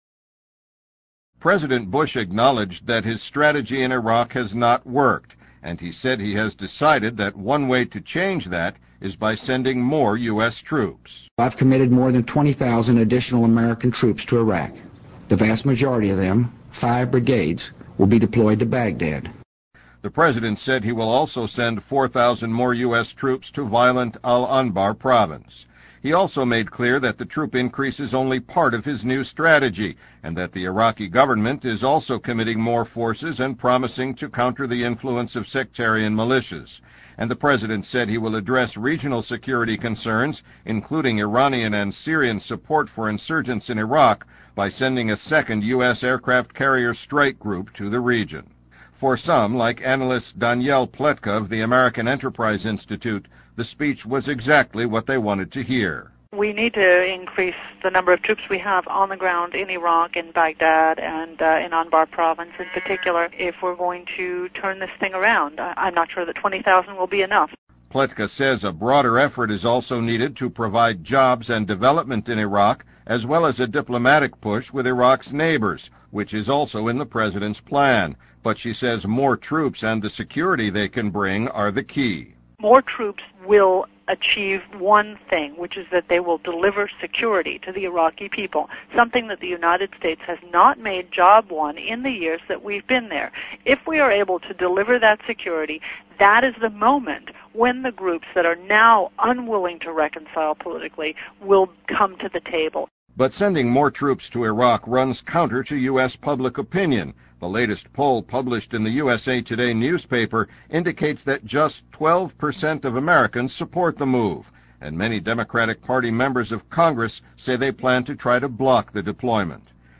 2007年VOA标准英语-Sharp Disagreements Voiced on Bush's Iraq Troop 听力文件下载—在线英语听力室